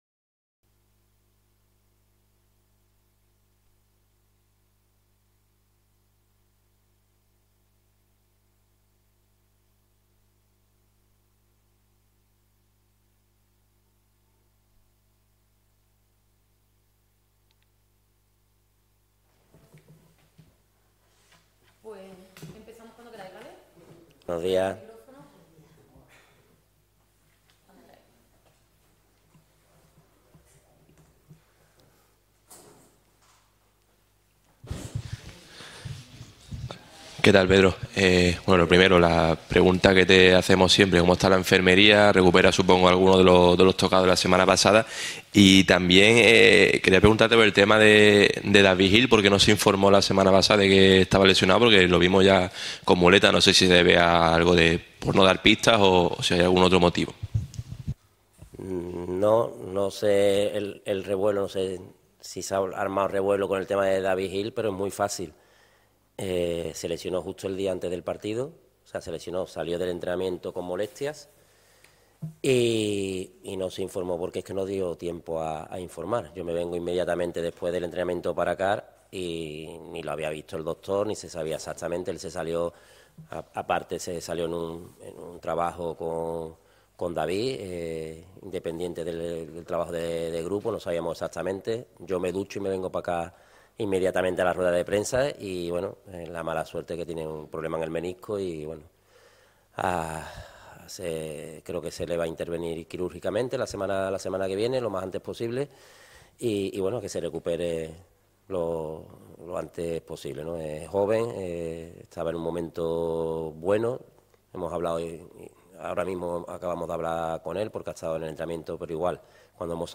la habitual rueda de prensa previa